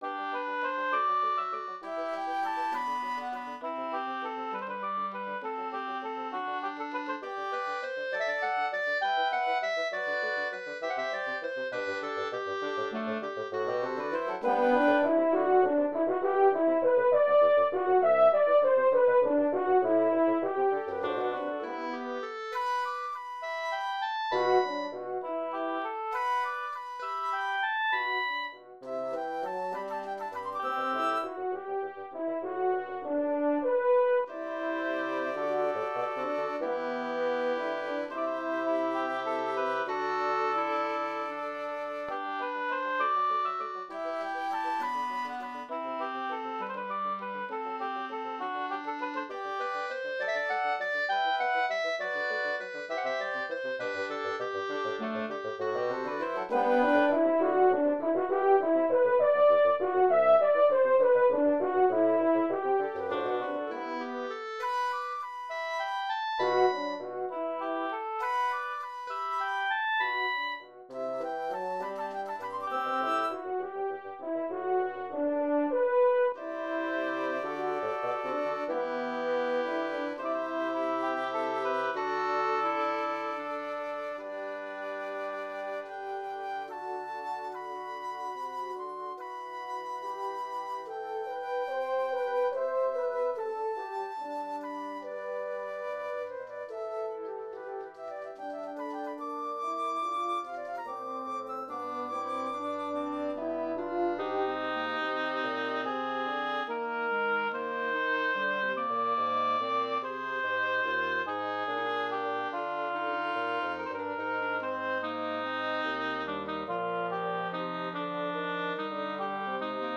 quinteto de viento madera